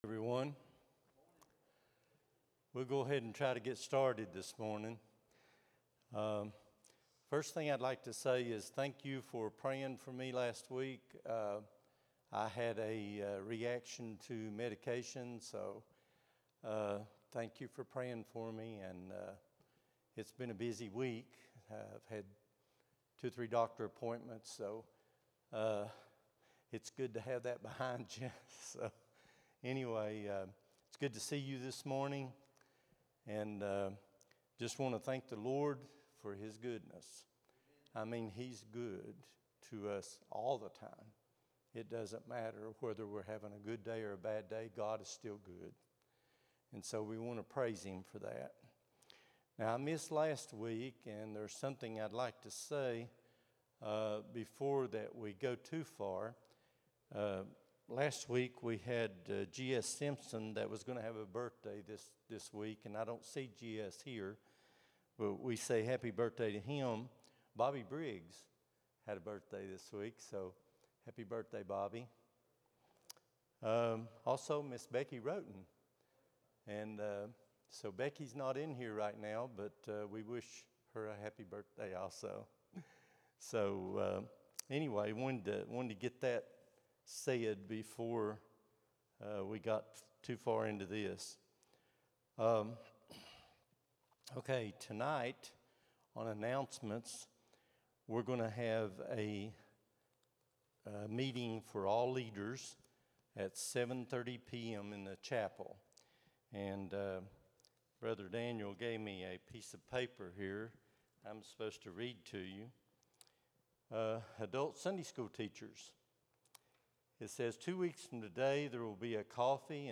10-05-25 Sunday School | Buffalo Ridge Baptist Church